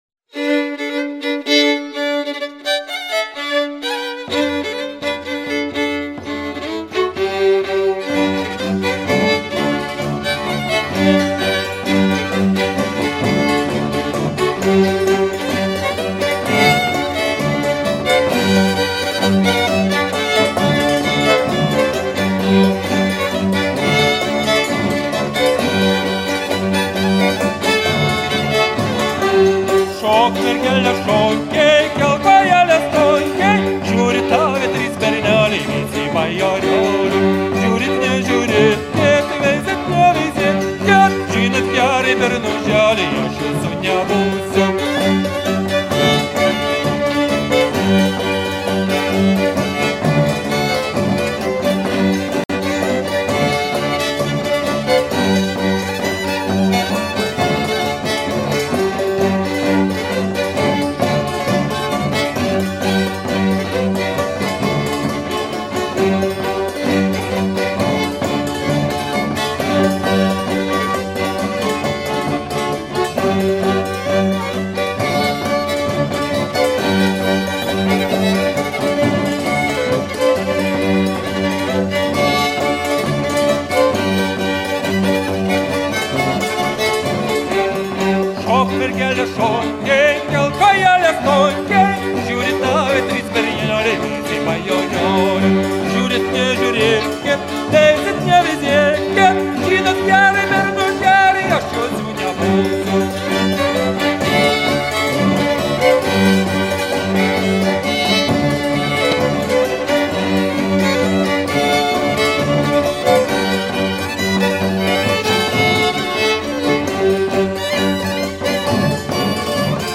Greicpolke. Zemait.mp3